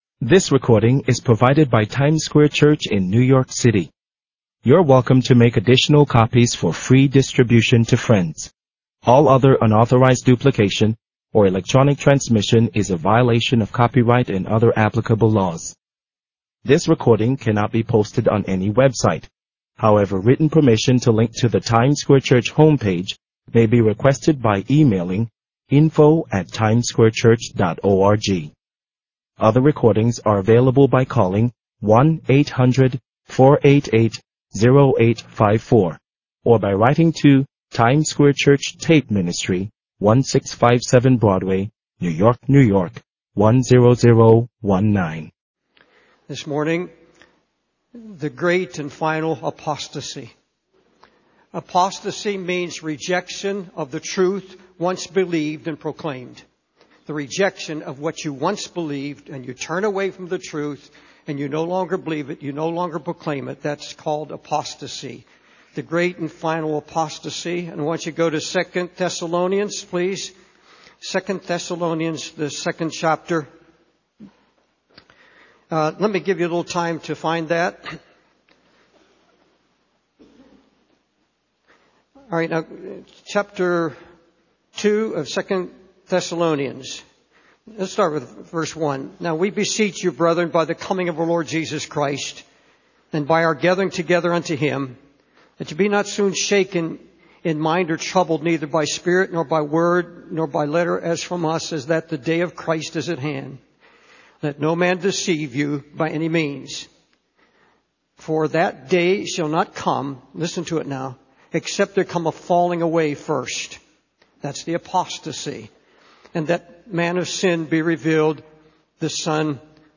In this sermon, the preacher expresses his concern about the state of the church of Jesus Christ and its departure from its foundational principles. He draws a parallel between the ancient nation of Israel and the modern church, stating that both have forgotten their beginnings and become like a harlot. The preacher emphasizes the importance of preaching the full gospel without dilution or compromise, even if it may offend some.